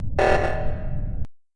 Among Us Alert Buzzer